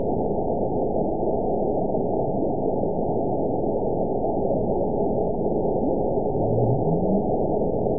event 922293 date 12/29/24 time 04:40:15 GMT (11 months, 1 week ago) score 9.57 location TSS-AB04 detected by nrw target species NRW annotations +NRW Spectrogram: Frequency (kHz) vs. Time (s) audio not available .wav